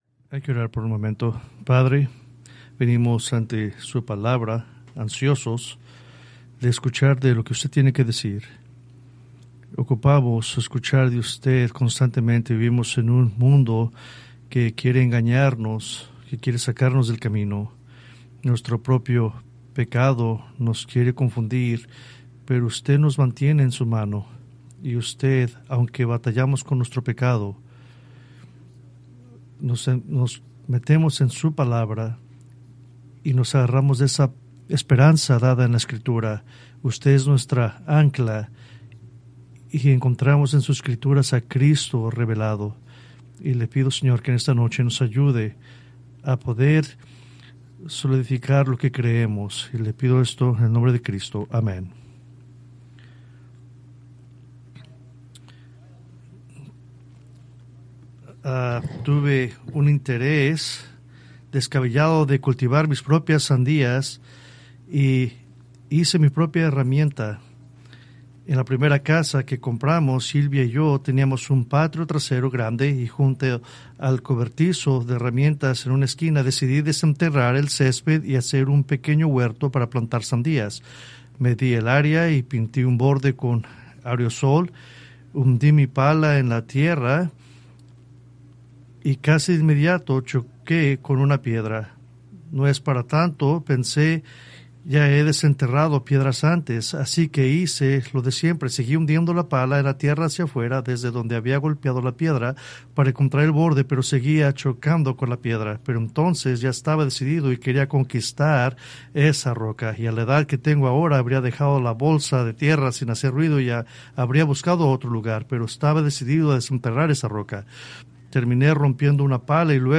Preached February 15, 2026 from Escrituras seleccionadas